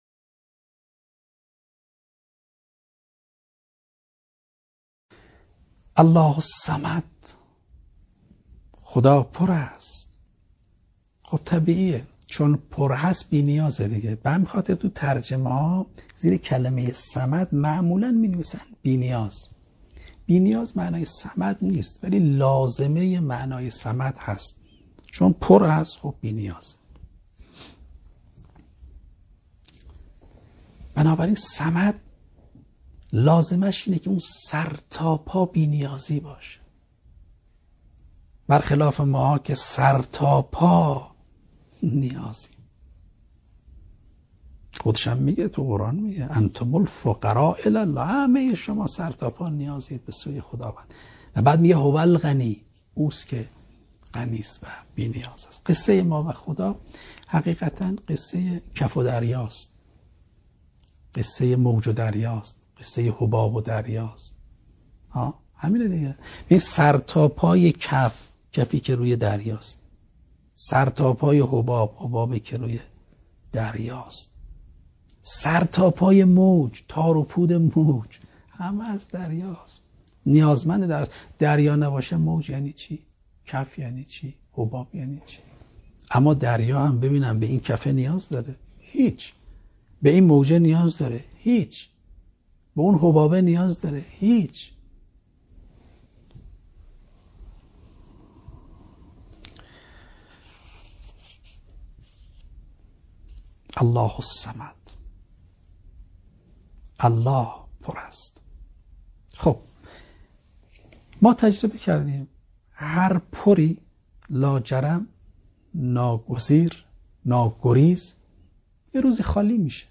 تفسیر صوتی سوره توحید قرآن کریم
به صورت زنده برگزار می شود